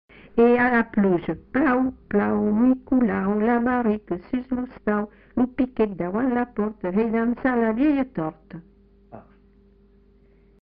Lieu : Captieux
Genre : forme brève
Type de voix : voix de femme
Production du son : chanté
Classification : formulette enfantine